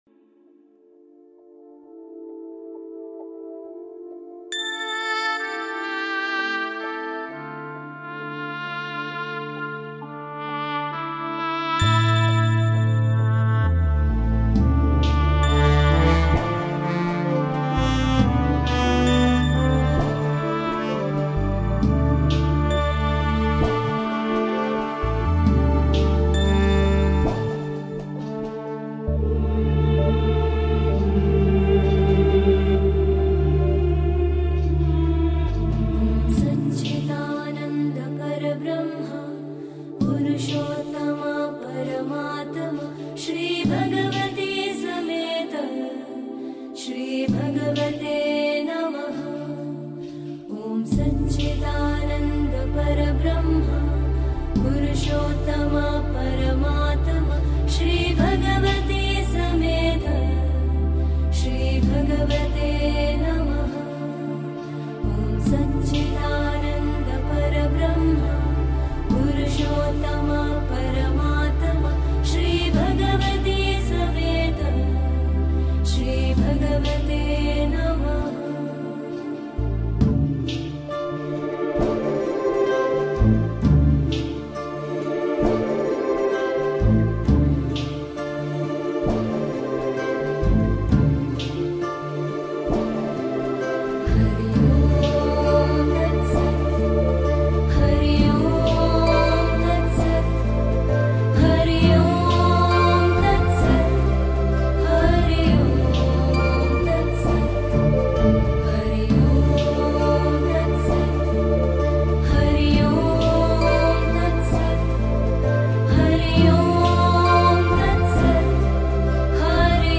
Als Ausdruck für den angestrebten inneren Frieden singt oder chantet (rezitiert) man das Moola-Mantra feierlich.